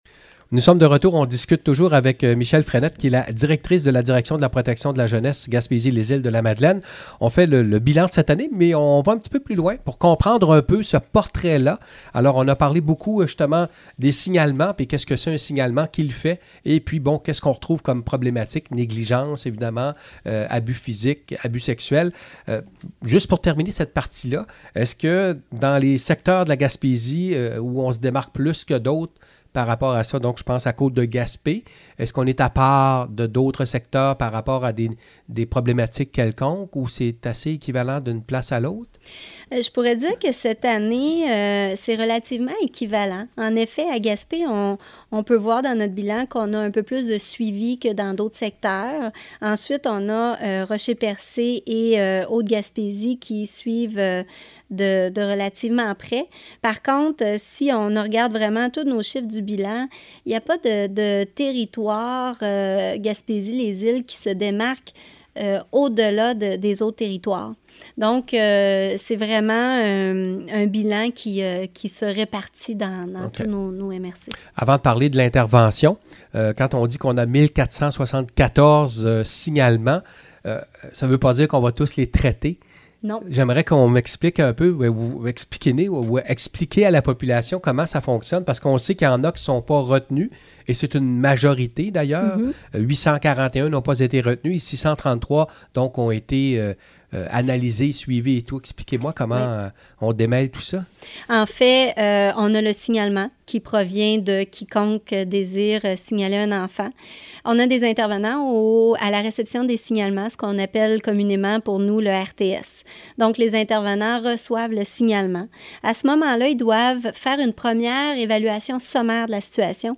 Deuxième partie de l’entrevue: